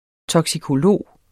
Udtale [ tʌgsikoˈloˀ ]